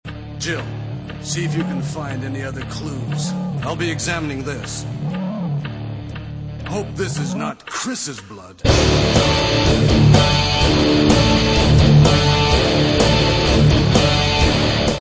neo métal